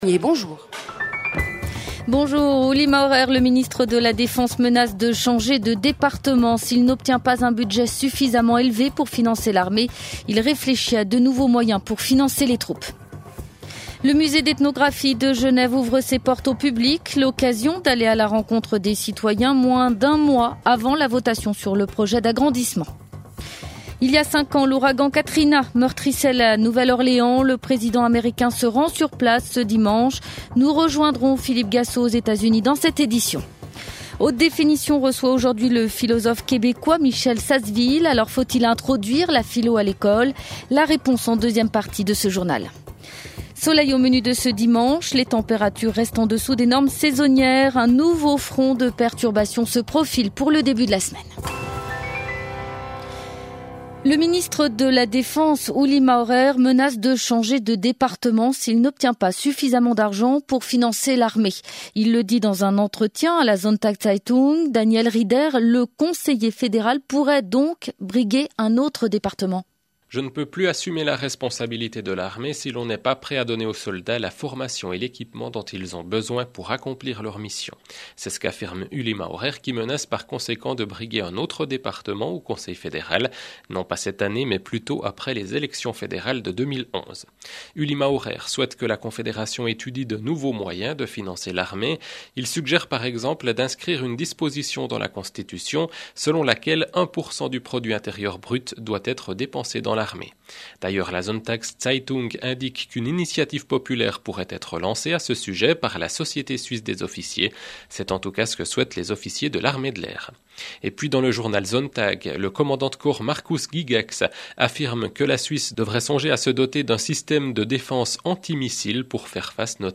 Le 12h30, c’est le grand rendez-vous d’information de la mi-journée. L’actualité dominante y est traitée, en privilégiant la forme du reportage/témoignage pour illustrer les sujets forts du moment.